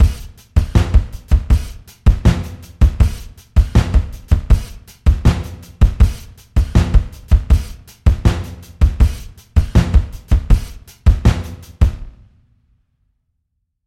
描述：摇滚鼓循环
标签： 160 bpm Ambient Loops Drum Loops 2.33 MB wav Key : Unknown
声道立体声